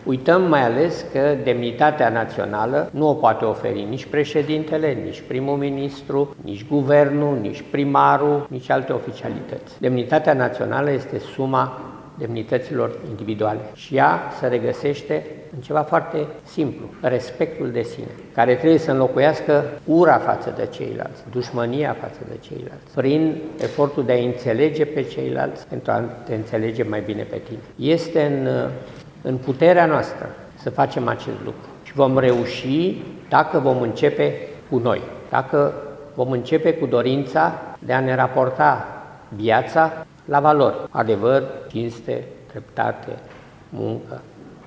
Universitatea de Medicină și Farmacie din Tîrgu-Mureș a găzduit azi evenimentul de lansare a cărții „Prețul demnității. O istorie altfel”, scrisă de Laura Ganea.
Fostul președinte al României le-a oferit celor prezenți și câteva sfaturi.
Emil Constantinescu a subliniat că schimbarea trebuie să plece de la fiecare în parte, prin respectarea unor reguli simple: